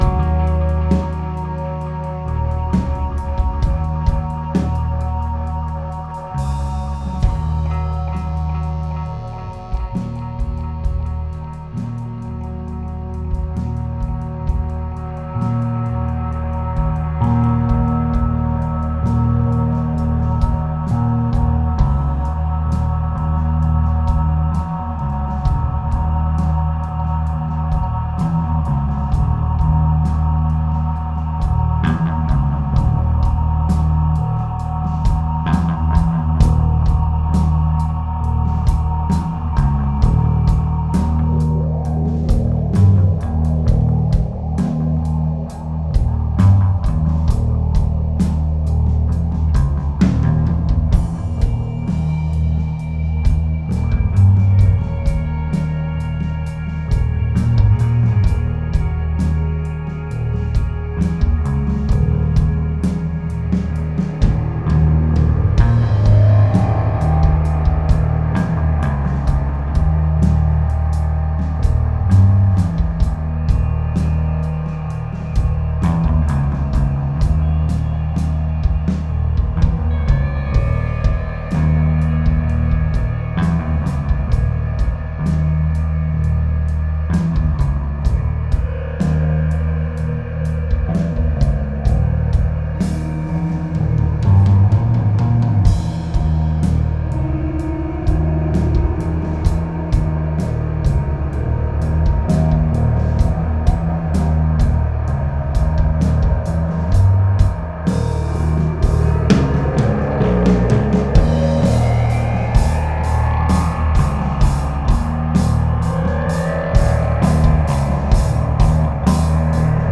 bass, sitar, synthesizer, mellotron
drums, percussion
saxophone